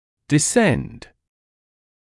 [dɪ’send][ди’сэнд]опускаться, снижаться